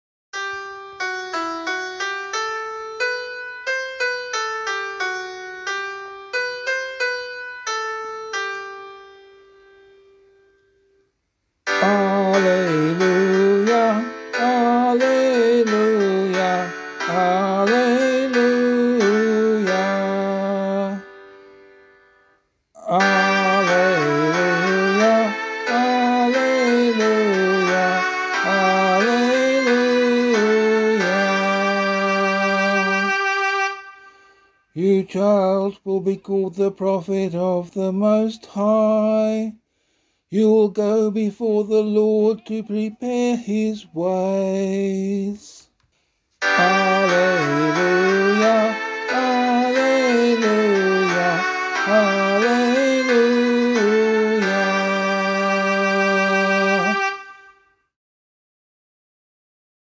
Gospelcclamation for Australian Catholic liturgy.